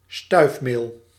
Ääntäminen
Ääntäminen : IPA: [pɔ.lɛn] Haettu sana löytyi näillä lähdekielillä: ranska Käännös Konteksti Ääninäyte Substantiivit 1. stuifmeel {n} kasvitiede Muut/tuntemattomat 2. pollen Suku: m .